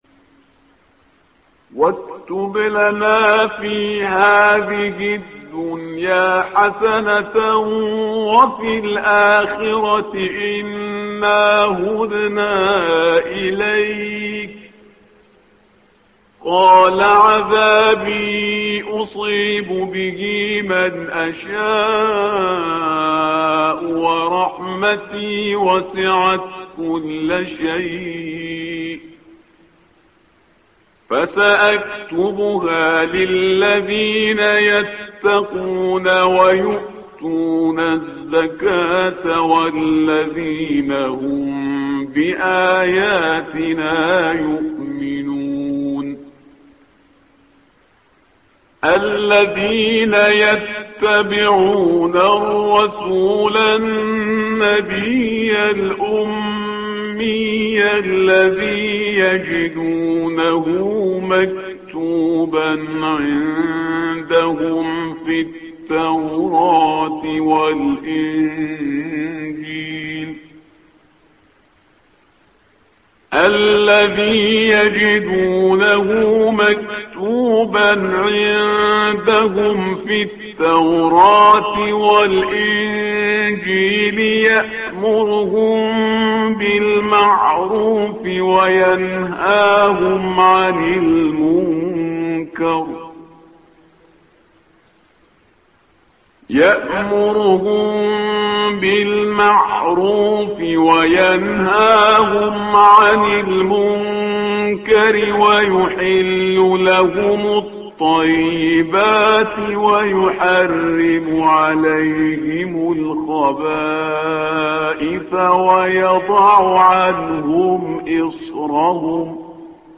تحميل : الصفحة رقم 170 / القارئ شهريار برهيزكار / القرآن الكريم / موقع يا حسين